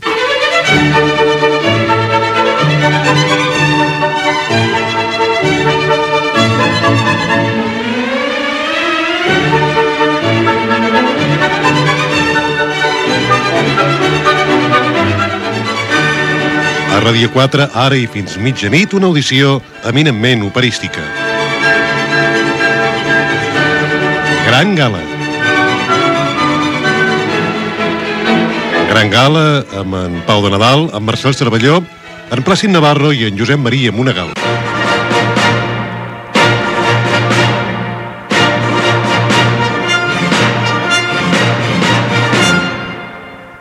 f31d8f5fbd963370f4dc5270d610c8391ed6e959.mp3 Títol Ràdio 4 Emissora Ràdio 4 Cadena RNE Titularitat Pública estatal Nom programa Gran gala Descripció Sintonia i presentació del programa amb els noms de l'equip. Gènere radiofònic Musical